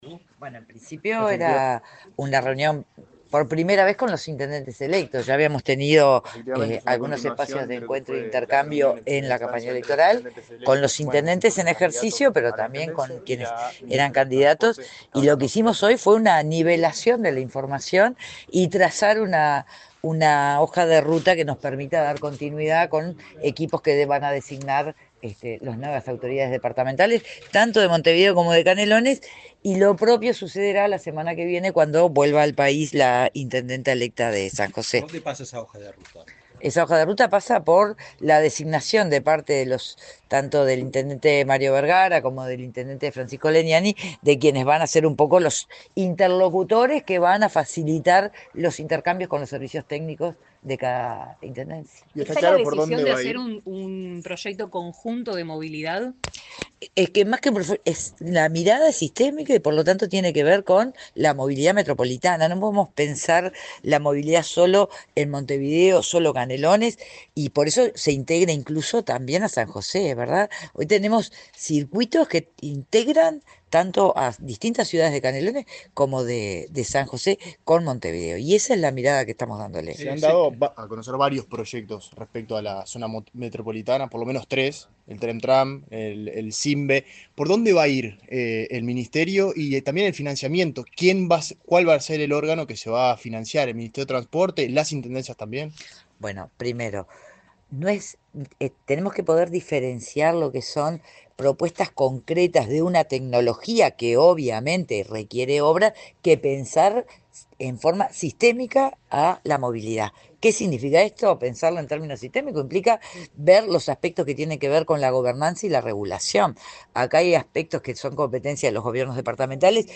Declaraciones de la ministra de Transporte y Obras Públicas, Lucía Etcheverry
Declaraciones de la ministra de Transporte y Obras Públicas, Lucía Etcheverry 03/06/2025 Compartir Facebook X Copiar enlace WhatsApp LinkedIn La ministra de Transporte y Obras Públicas, Lucía Etcheverry, dialogó con la prensa tras reunirse con los intendentes electos de Montevideo y Canelones.